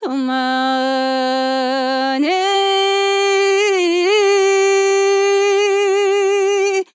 TEN VOCAL FILL 3 Sample
Categories: Vocals Tags: dry, english, female, fill, sample, TEN VOCAL FILL, Tension
POLI-VOCAL-Fills-100bpm-A-3.wav